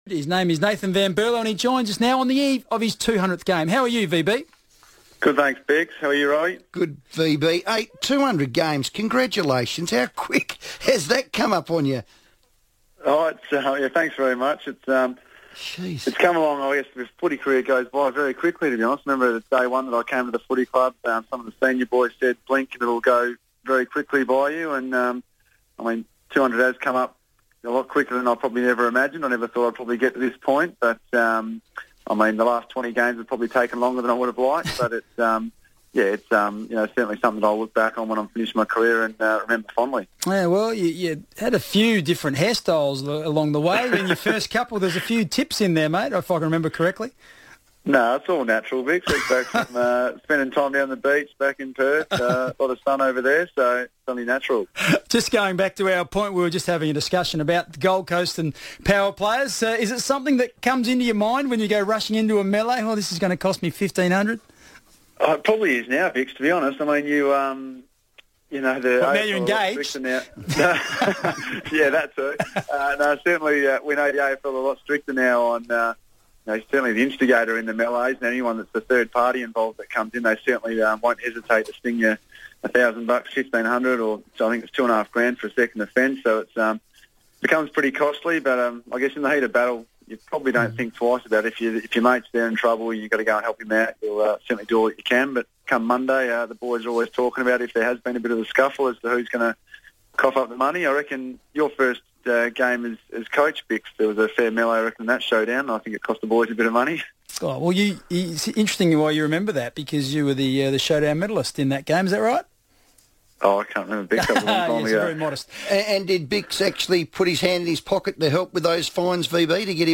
Nathan van Berlo spoke on the FIVEaa Sports Show ahead of his 200th game this weekend against Geelong